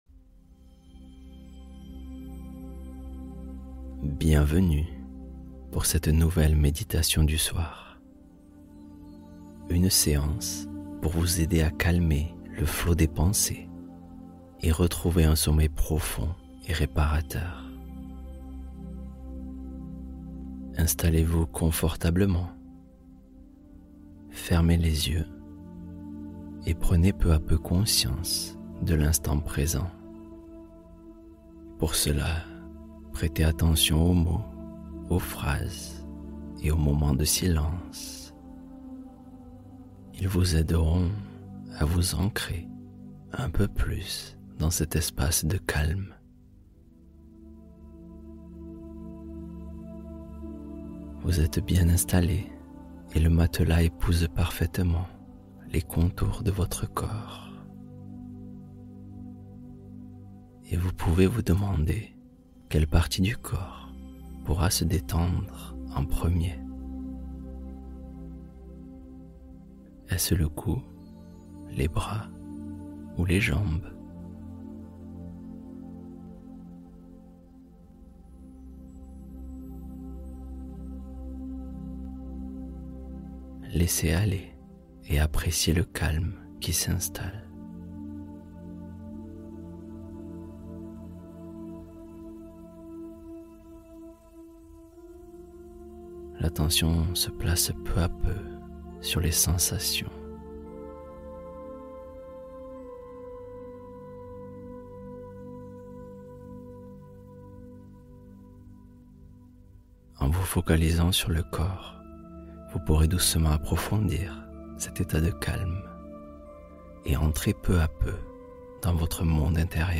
Repos Absolu : Conte du soir pour une immersion dans le calme